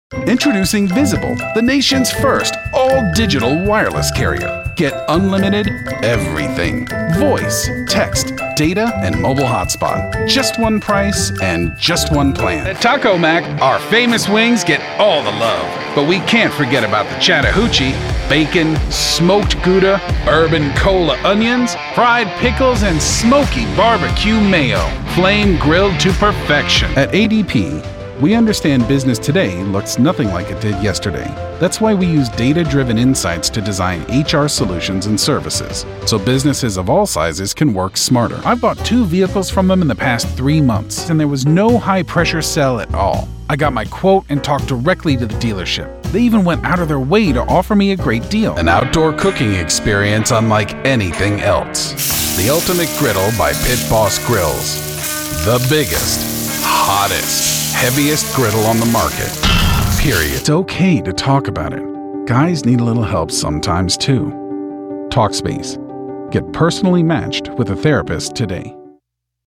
Commercial Demo
English - USA and Canada
Young Adult
Middle Aged